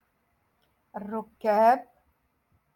Moroccan Dialect-Rotation Six- Lesson Nineteen